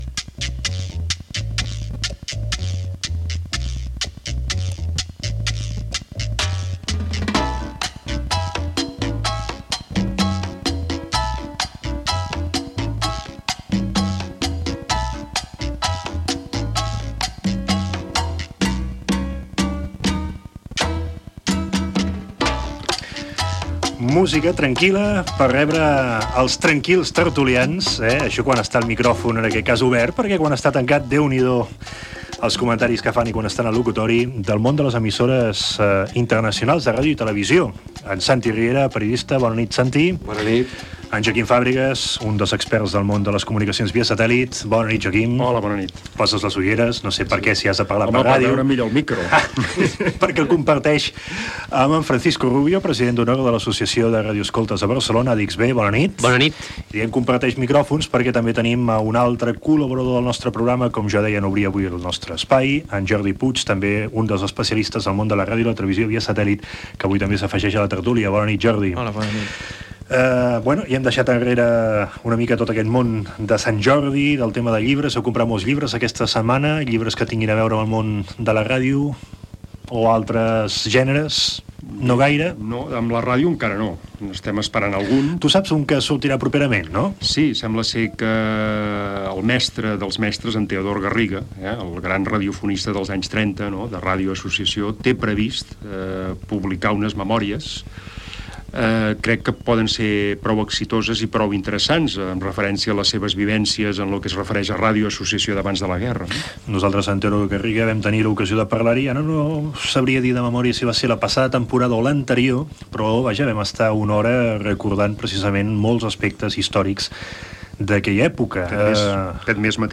Secció "Tertúlia de la ràdio i televisió internacional": llibres sobre Teodor Garriga, Radio Juventud i Història de la Ràdio a Mallorca; revistes DXistes canàries; novetats de la TV via satèl·lit amb TV Martí
Divulgació